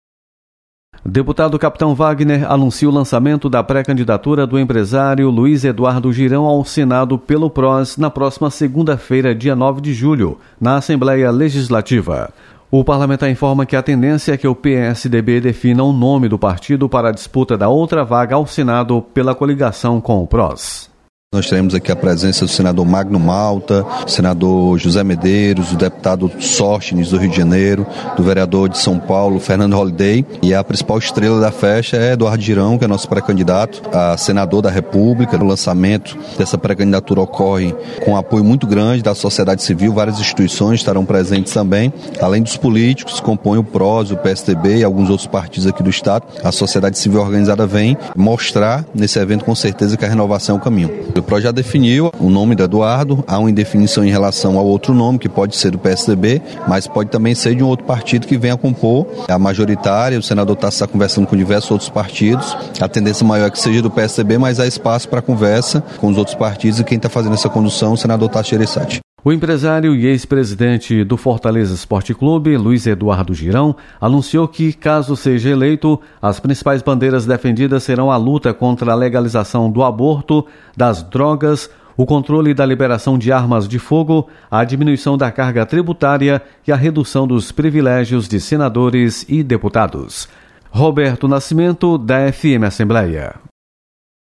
Deputado Capitão Wagner anuncia lançamento de pré-candidatura do Pros ao Senado. Repórter